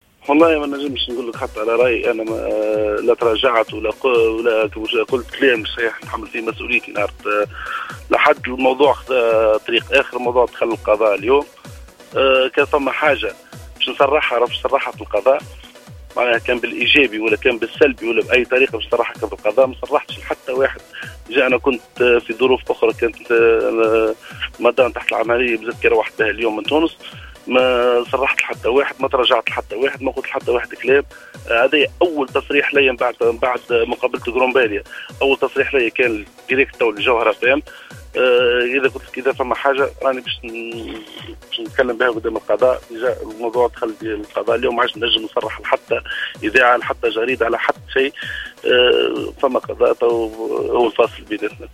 تصريح خاص لجوهرة اف أم